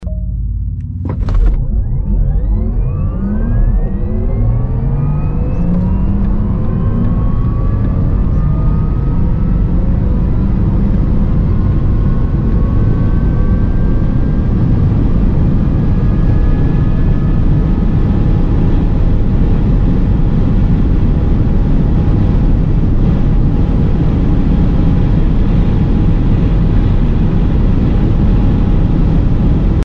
Звуки электромобиля
На этой странице собраны звуки электромобилей — от мягкого гула двигателя до предупреждающих сигналов.